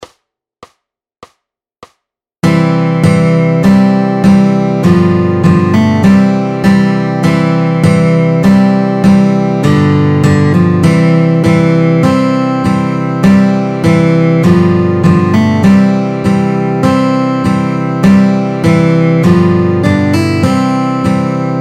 Formát Kytarové album
Hudební žánr Vánoční písně, koledy